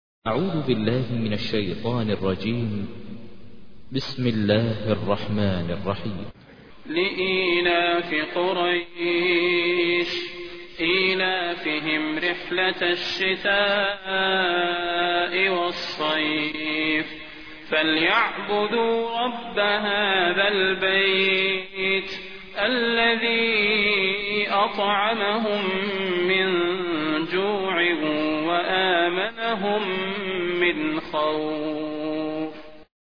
تحميل : 106. سورة قريش / القارئ ماهر المعيقلي / القرآن الكريم / موقع يا حسين